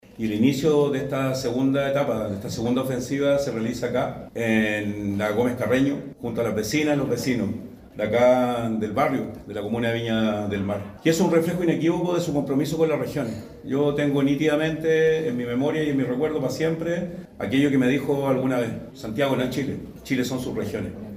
A la actividad también asistió el gobernador regional de Valparaíso, Rodrigo Munduca, quien destacó el compromiso de la exministra del Trabajo con las regiones.